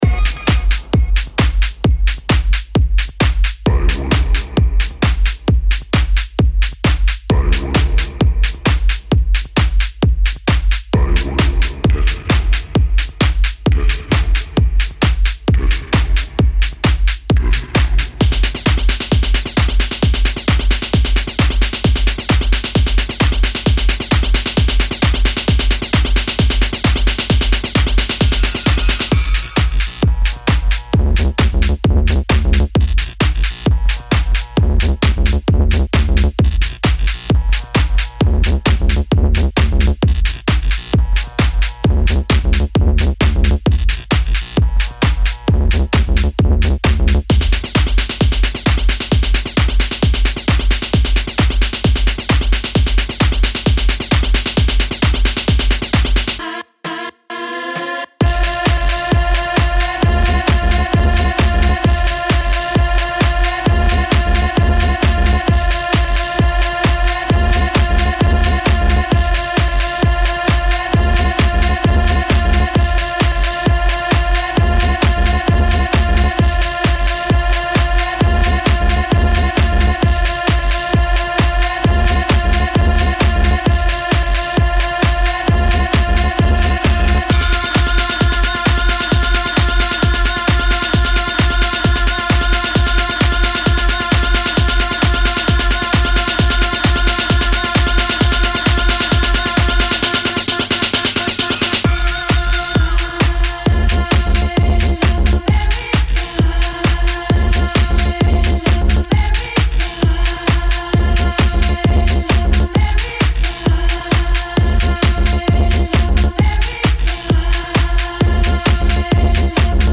Deep Groove Dark Techno